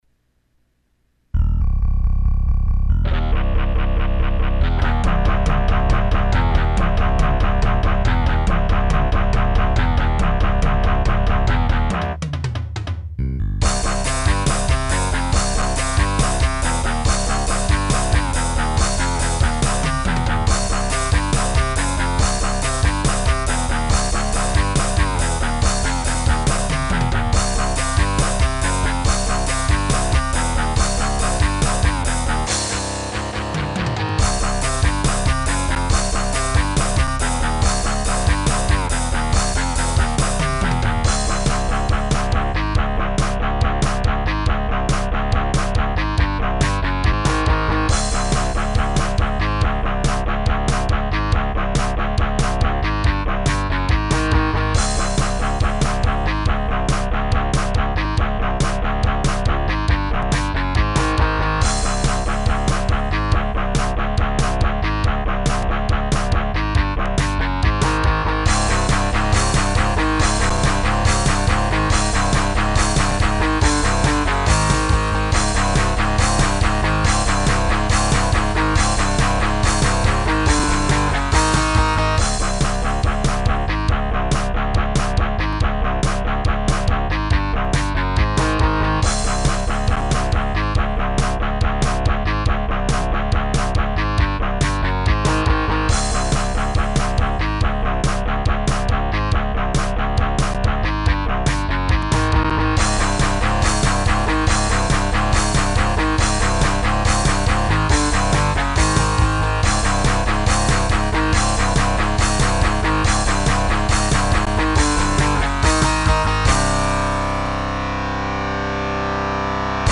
Y estan en MIDI, algun dia estaran grabadas.